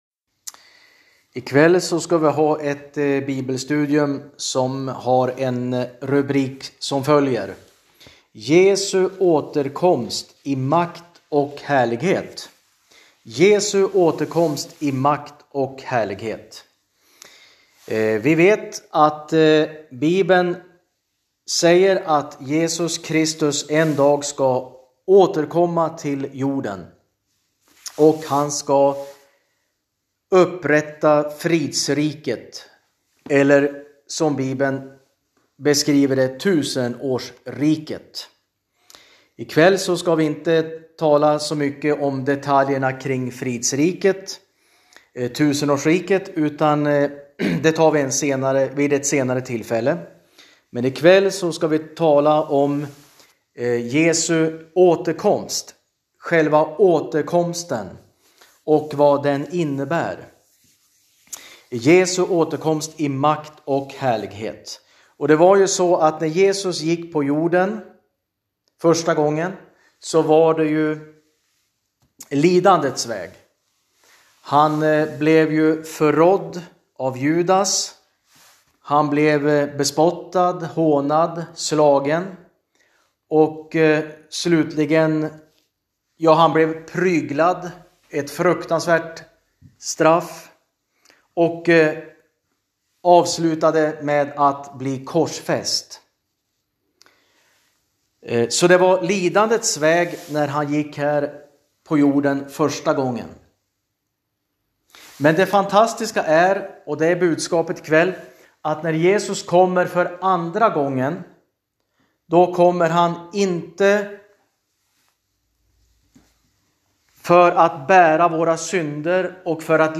Undervisning